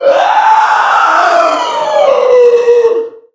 NPC_Creatures_Vocalisations_Puppet#5 (hunt_05).wav